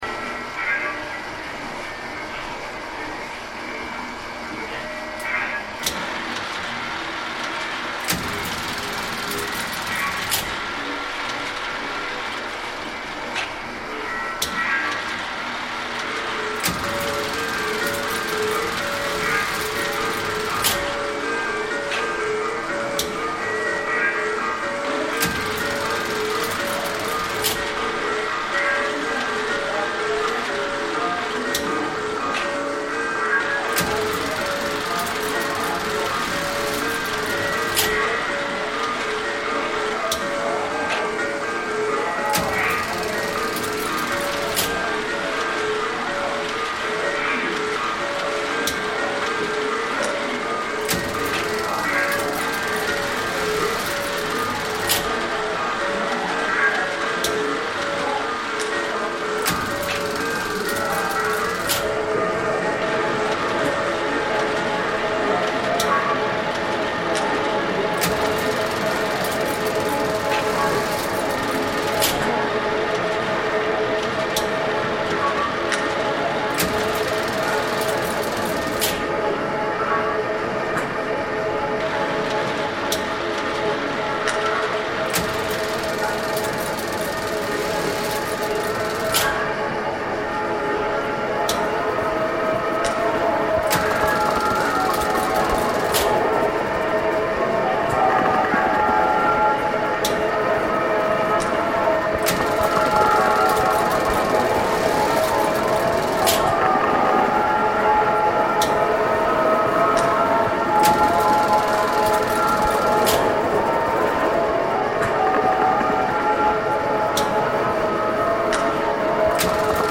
uses a single recording of the 1939 Bombe computer